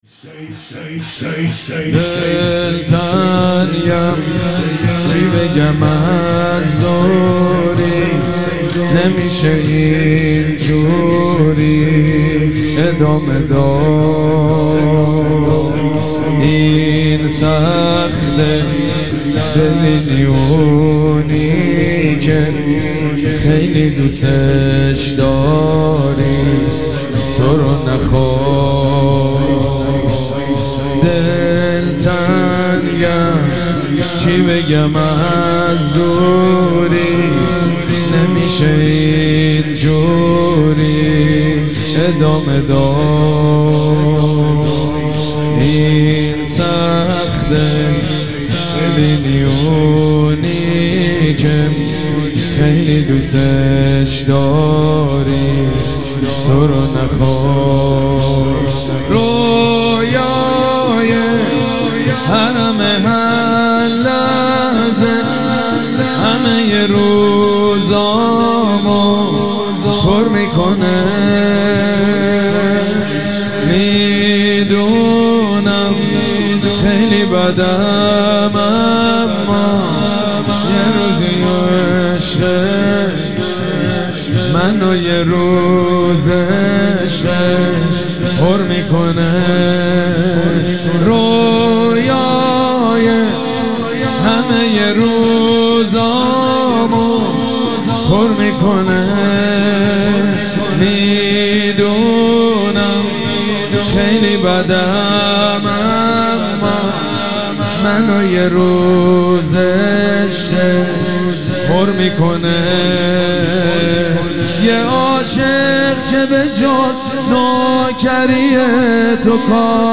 شب دوم مراسم عزاداری اربعین حسینی ۱۴۴۷
شور
مداح
حاج سید مجید بنی فاطمه